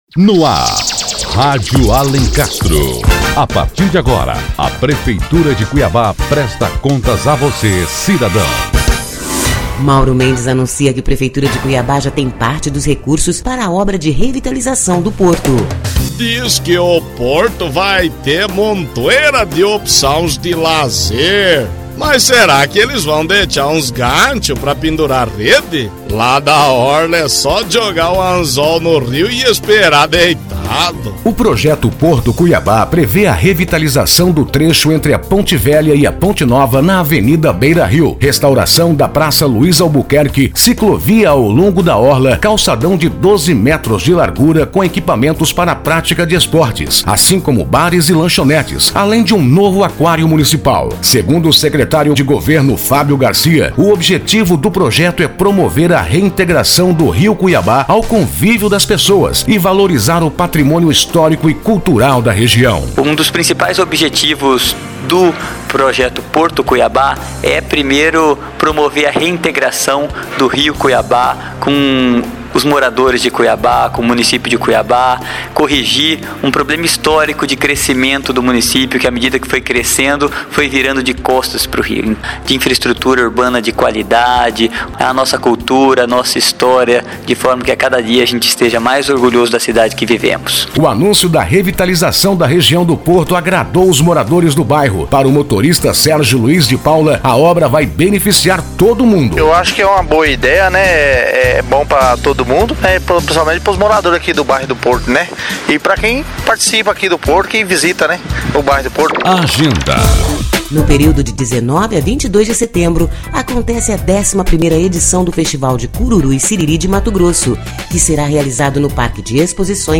Notícias / 14º Programa 18 de Fevereiro de 2014 16h07 Fique por dentro do Projeto Porto-Cuiabá Ouça o que o Secretário de Governo Fábio Garcia tem a dizer sobre o Porto-Cuiabá e saiba mais sobre este projeto da Prefeitura de Cuiabá.